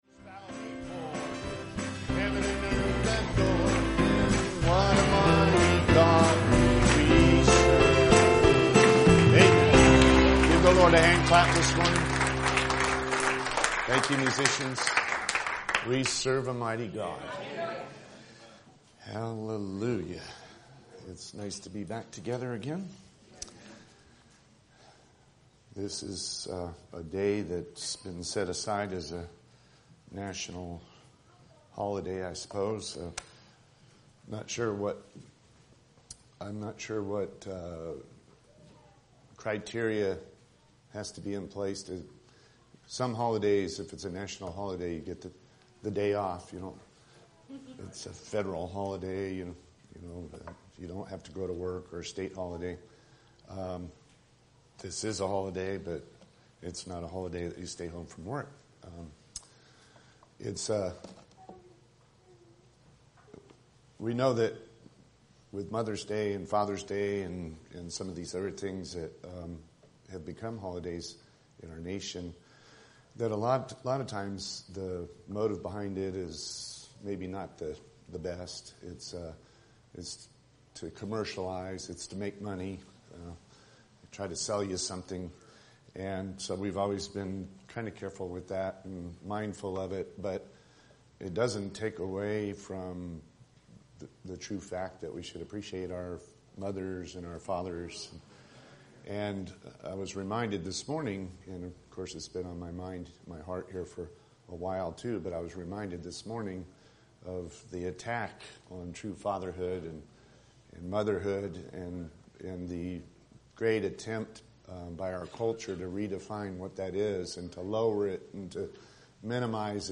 NOTE: Due to technical difficulties, this sermon’s video recording is not complete. There are small portions missing at the beginning, as well as the last part of the sermon is missing.